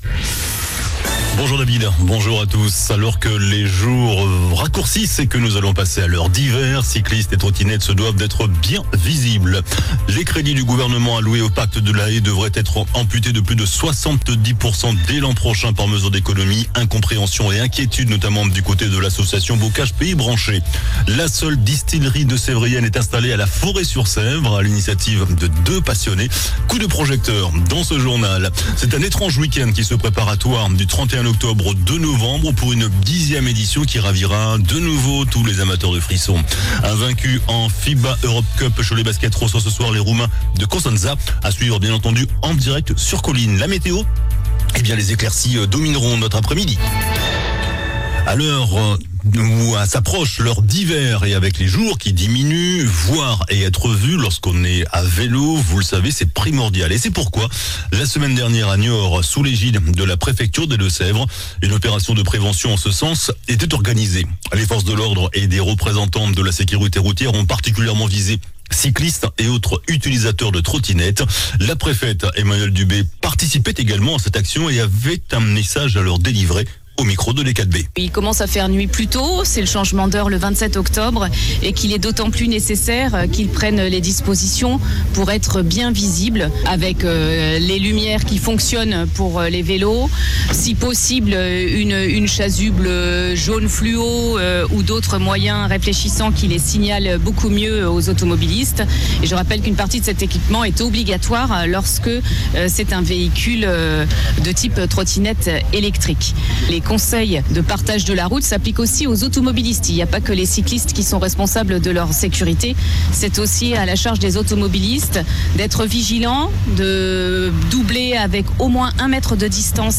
JOURNAL DU MERCREDI 23 OCTOBRE ( MIDI )